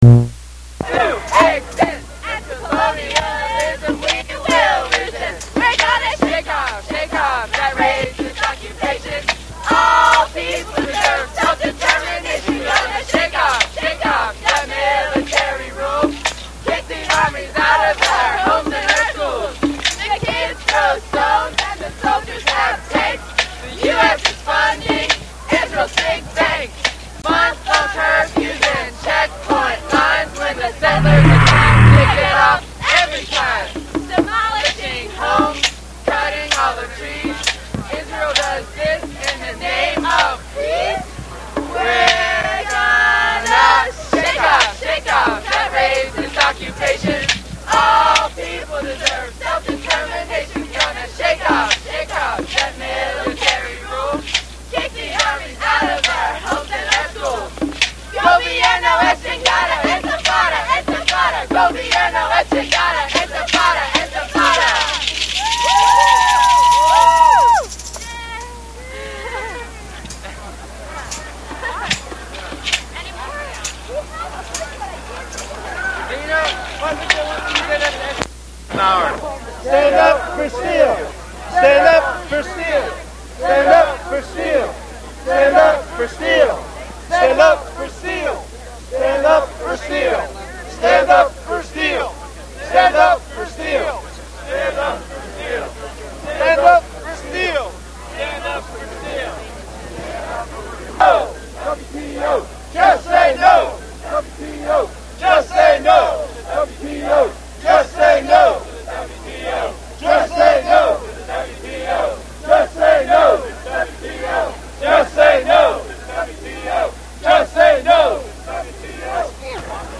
6 minutes and 43 seconds of action pcked audio from colorful pittsburgher's welcomeing the commander-in- cheif to a $2,000 a plate fund raiser for his presidential campaign...lots of angery steelworkers showed up in force. mp3 64 kbs 44mhz